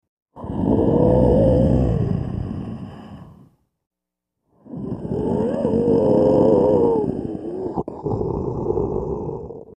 Alien Breathing; Large Creature Growl And Wheeze Breaths, Close Pov.